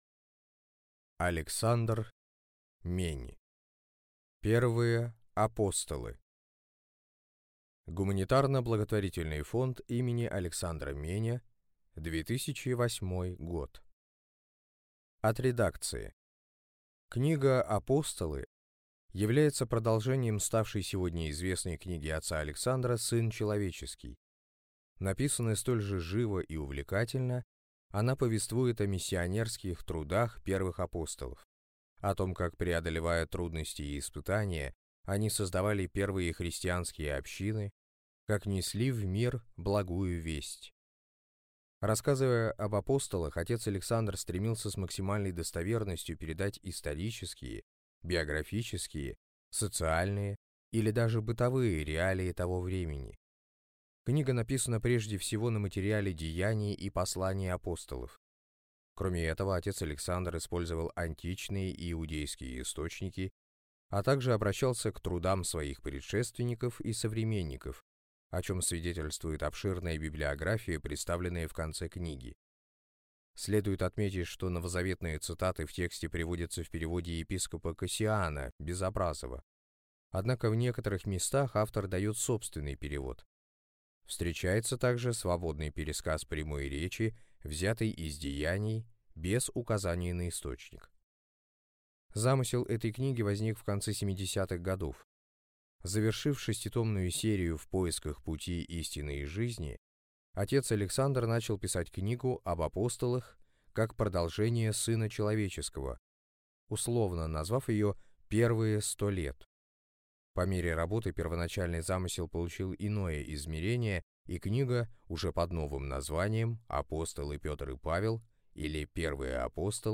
Аудиокнига Первые апостолы | Библиотека аудиокниг